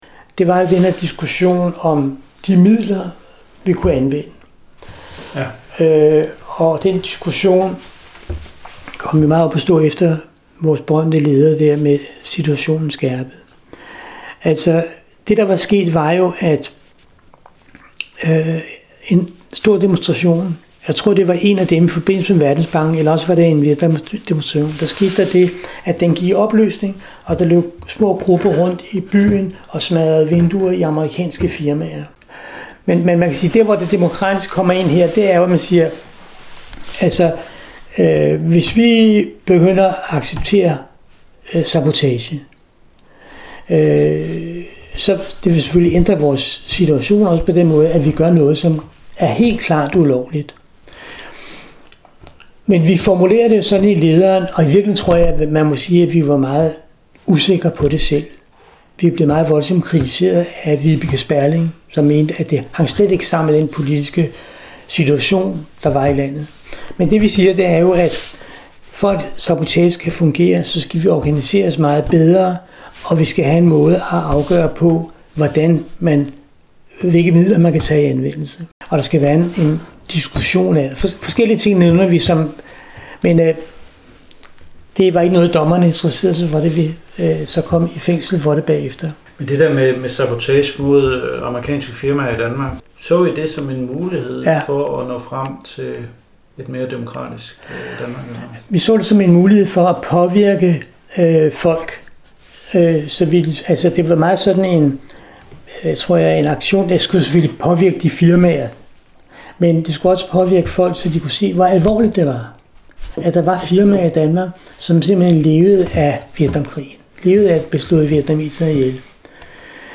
Kilde 3: Erindring